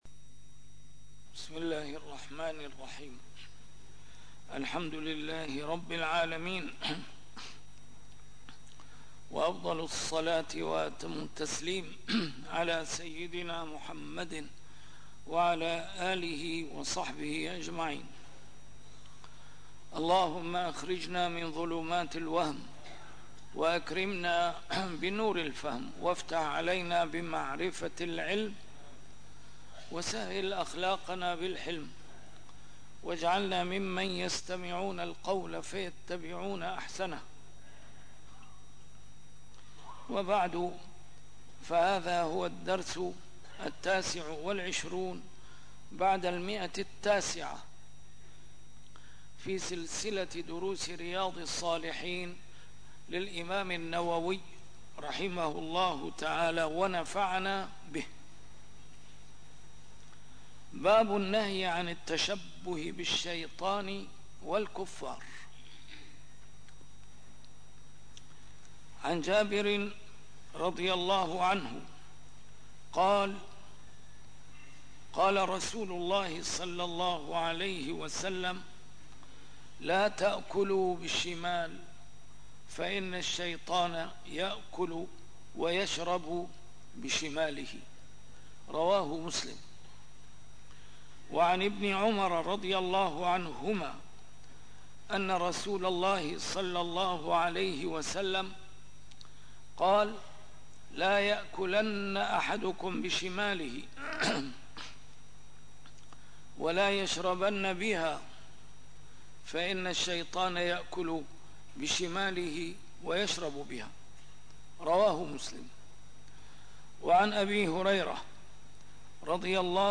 A MARTYR SCHOLAR: IMAM MUHAMMAD SAEED RAMADAN AL-BOUTI - الدروس العلمية - شرح كتاب رياض الصالحين - 929- شرح رياض الصالحين: النهي عن التشبه بالشيطان والكفار